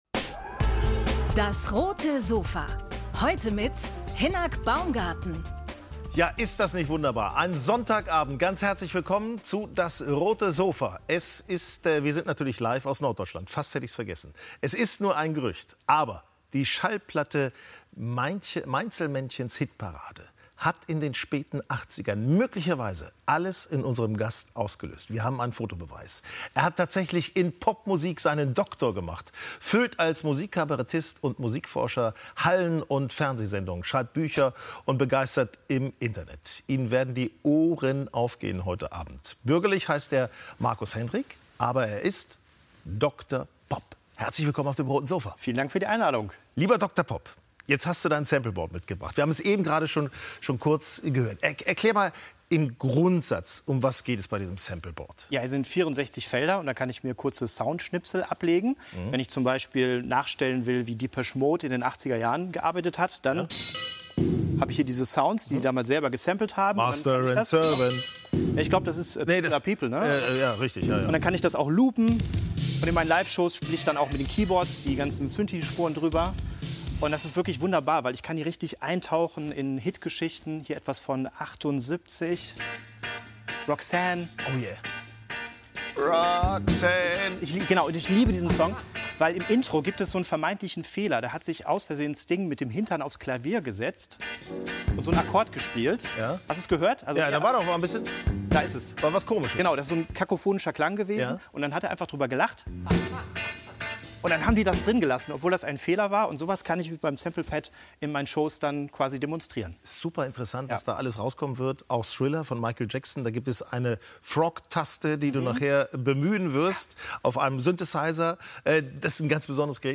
DAS! - täglich ein Interview Podcast